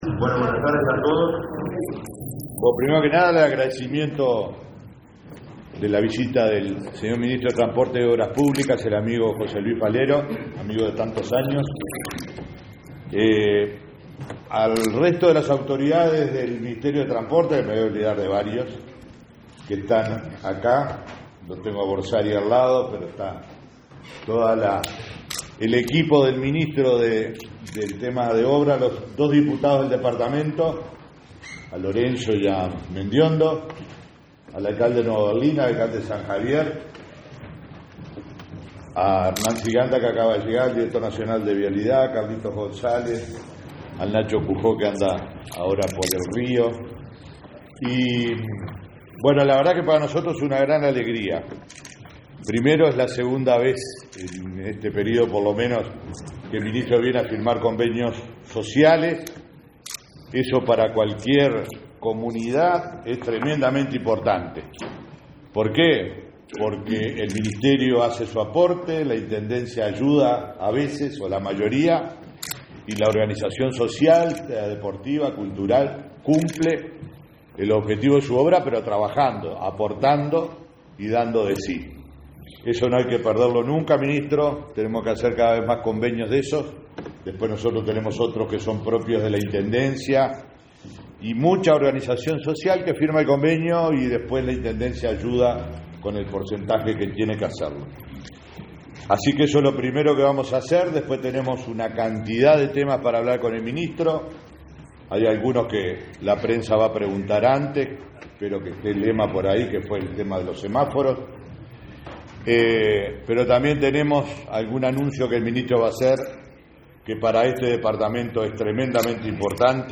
Palabras del ministro de Transporte y Obras Públicas, José Luis Falero